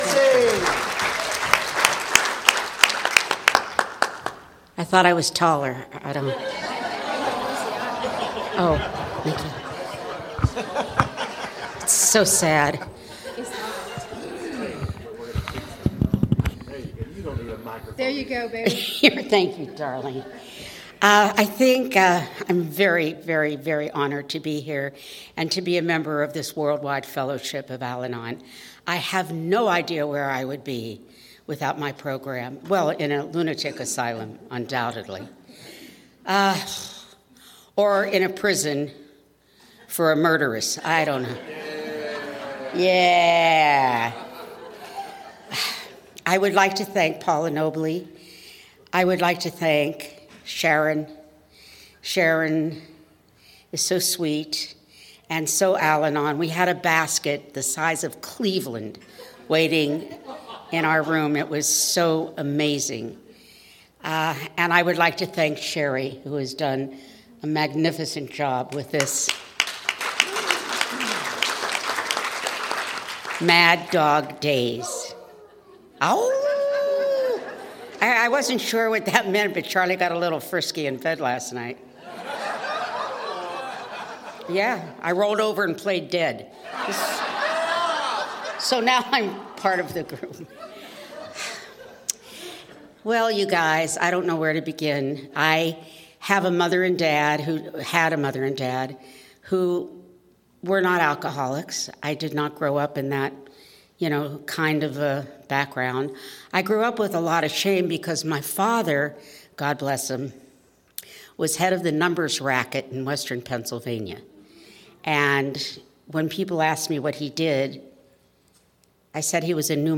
N Hollywood CA - Luncheon Speakers